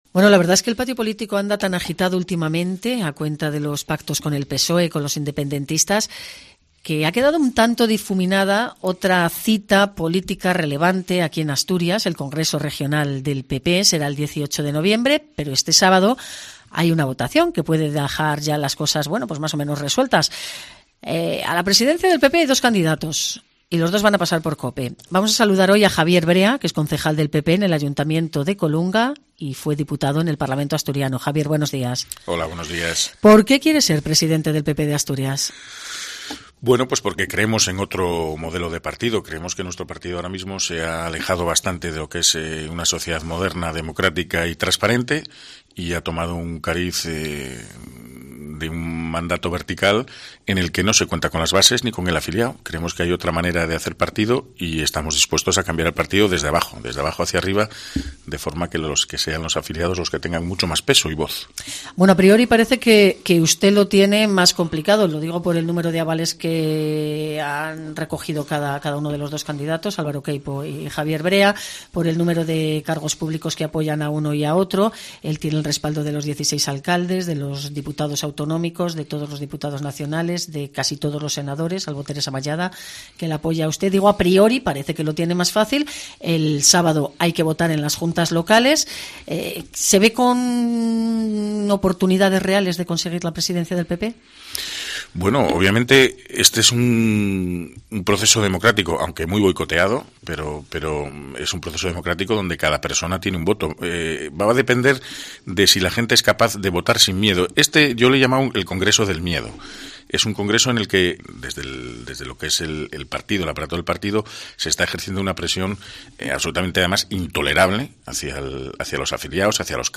Entrevista a javier Brea en COPE Asturias